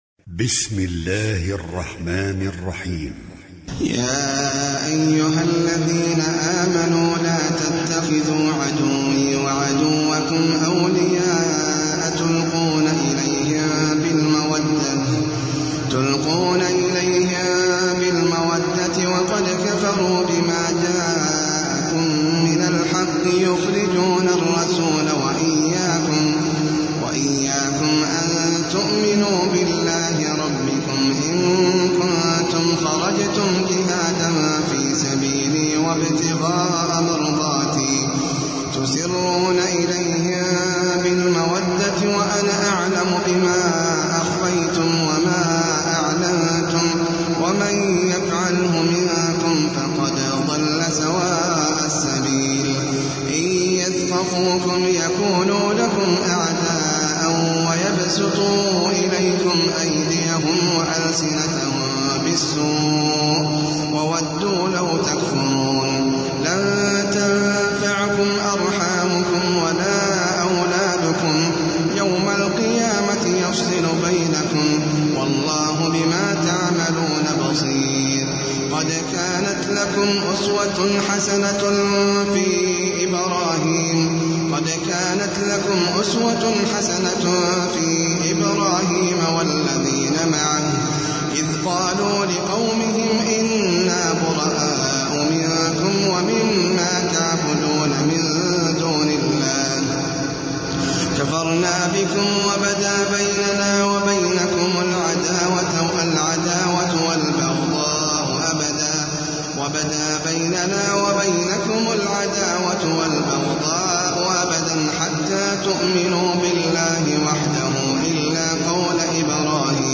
سورة الممتحنة - المصحف المرتل (برواية حفص عن عاصم)
جودة عالية